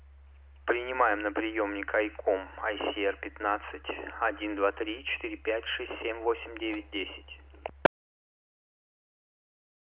Пример записи приема на карту SD (128 КБ/сек) в аналоговом режиме без обработки: